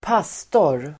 Uttal: [²p'as:tor]